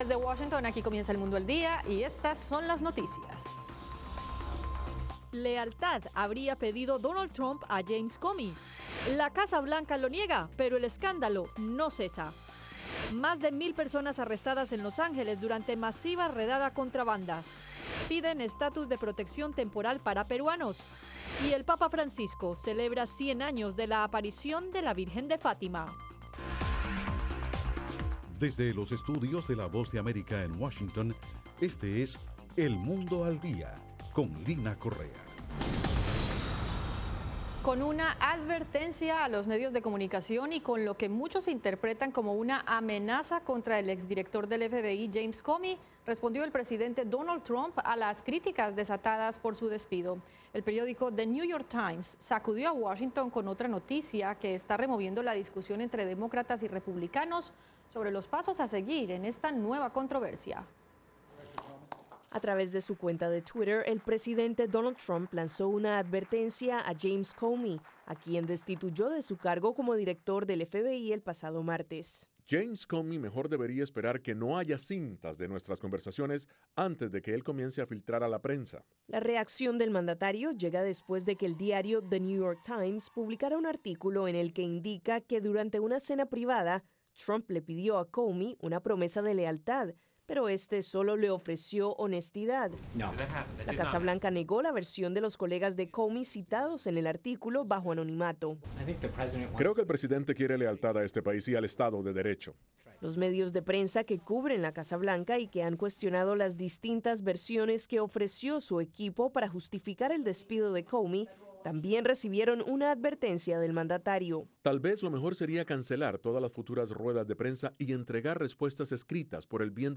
Las noticias del acontecer de Estados Unidos y el mundo